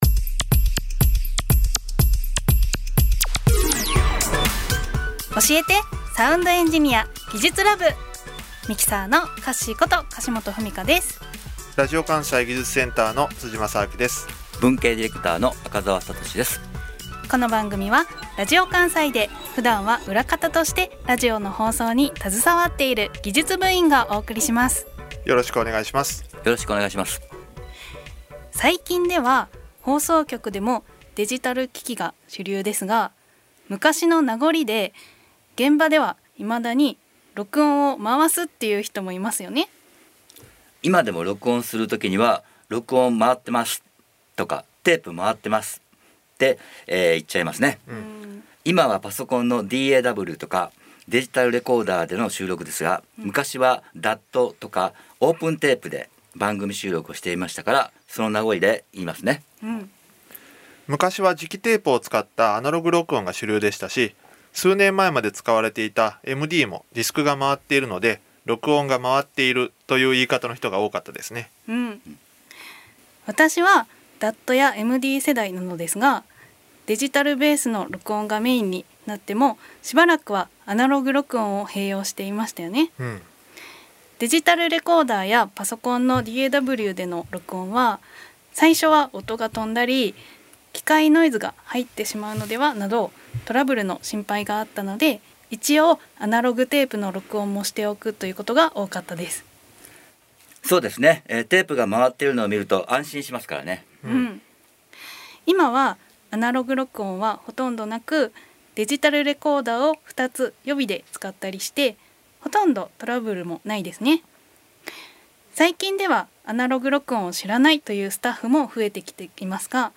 デジタル時代に残るアナログ録音の名残とはどんなものか、普段はラジオを陰で支えている技術スタッフがラジオ番組のなかで解説しました。
【放送音声】ラジオ関西『おしえて！サウンドエンジニア』2022年8月28日放送回